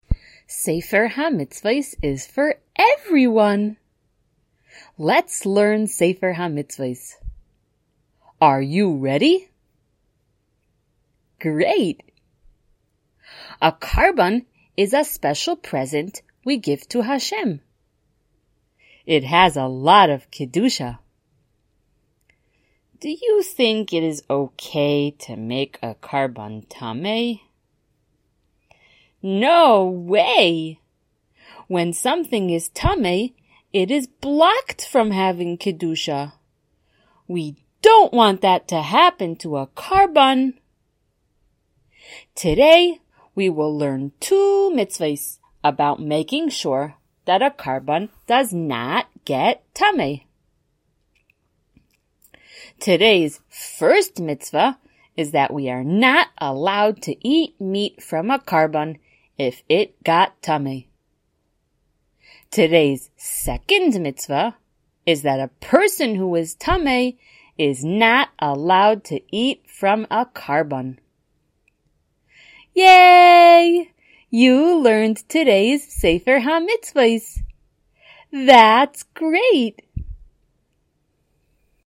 Color Shiur #172!
SmallChildren_Shiur172.mp3